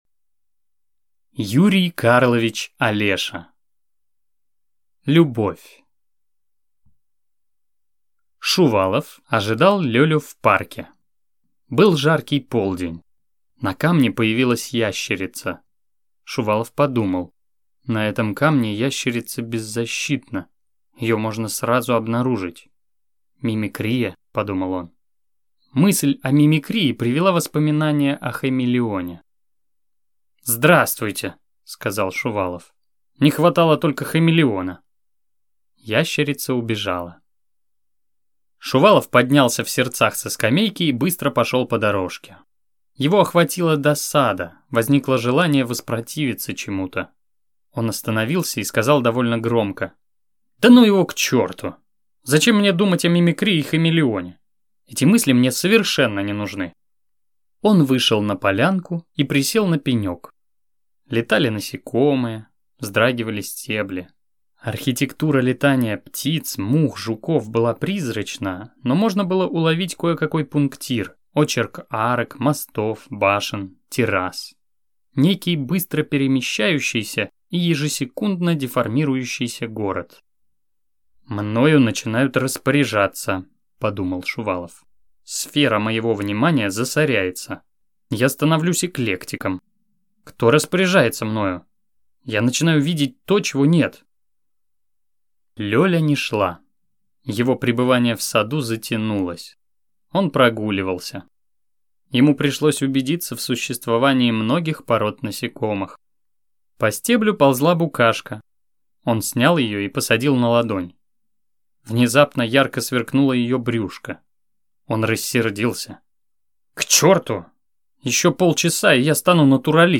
Аудиокнига Любовь | Библиотека аудиокниг